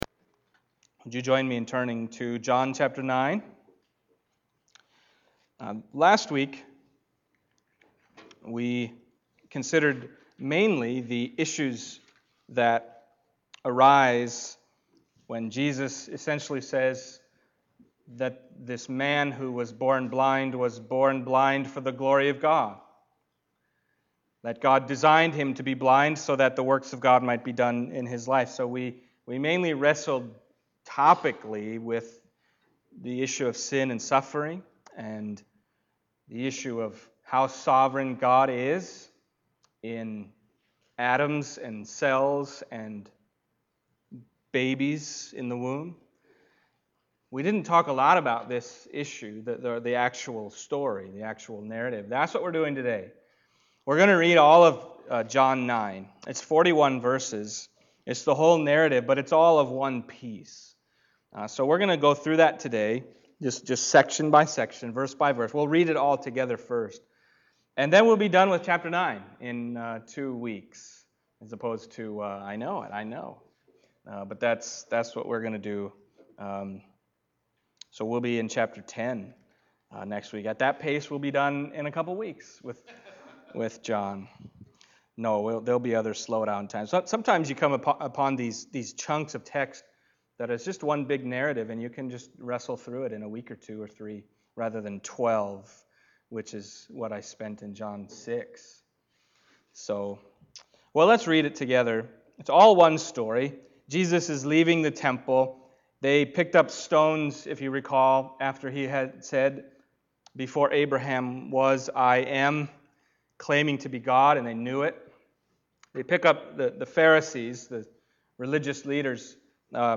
John Passage: John 9:1-41 Service Type: Sunday Morning John 9:1-41 « I Once Was Blind